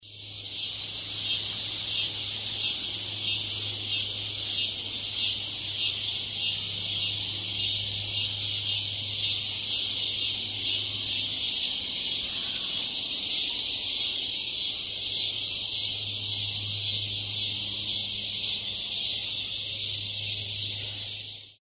Стрекотание этих насекомых идеально подходит для релаксации, звукового оформления или погружения в атмосферу дикой природы.